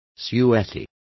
Complete with pronunciation of the translation of suety.